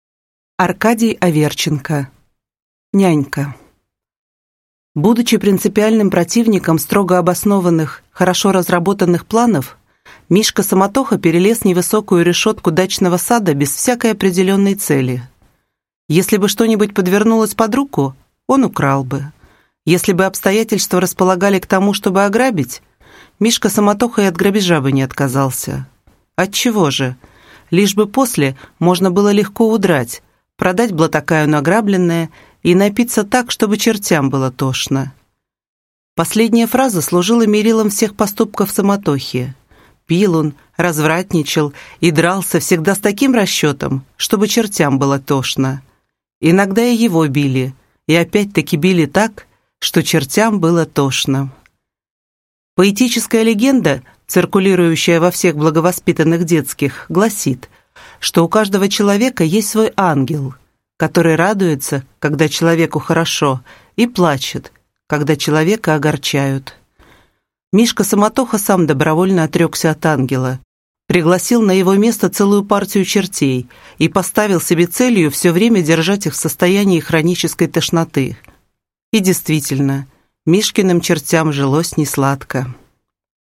Аудиокнига Нянька | Библиотека аудиокниг